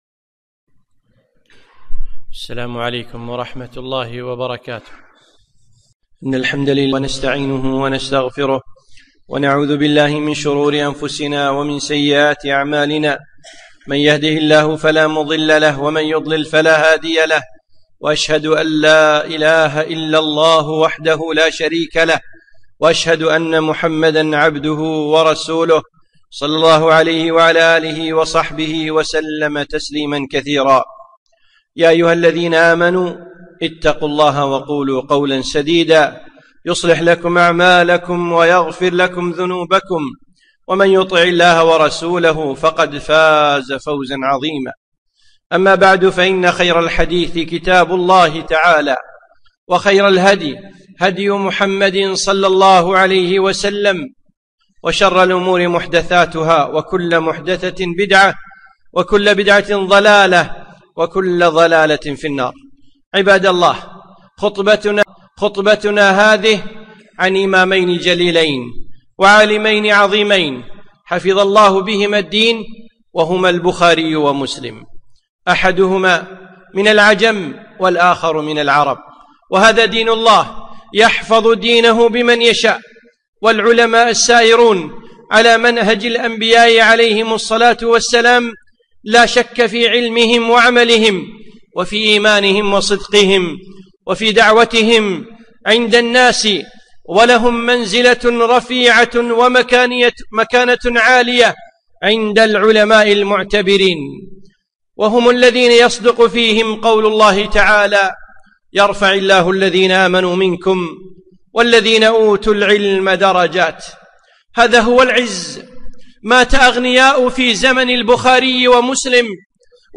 خطبة - الإمام البخاري